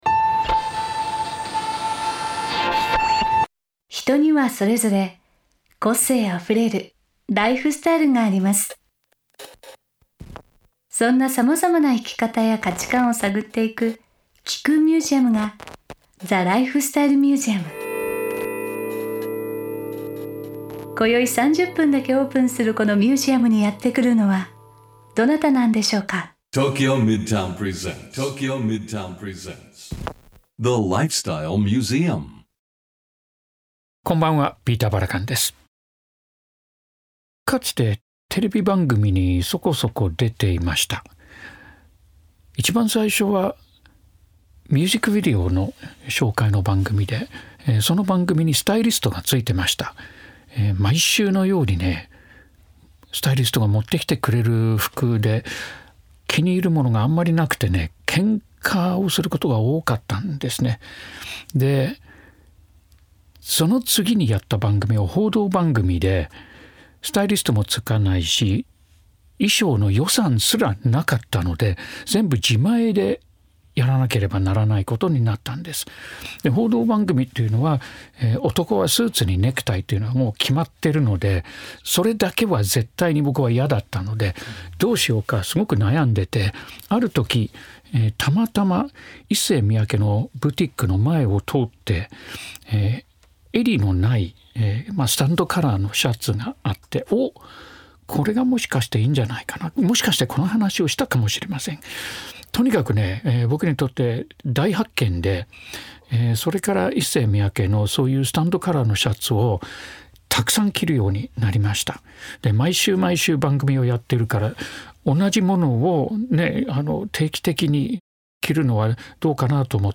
巨匠亡き後、ISSEY MIYAKEの「ものづくり」の思想は如何にして受け継がれていくのか？ 長年ISSEY MIYAKEの大ファンであるバラカンさんとの深いトークが展開されます。